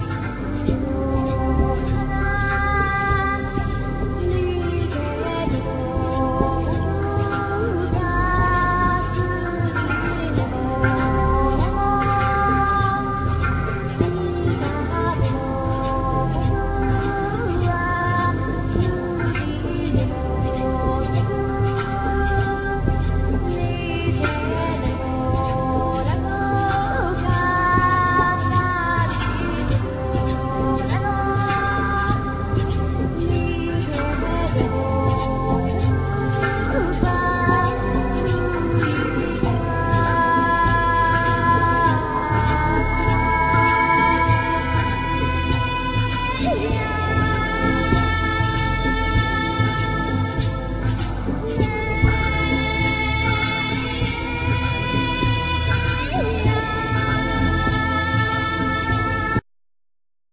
Guitar,Clarinett,Voice
Japanese orientalism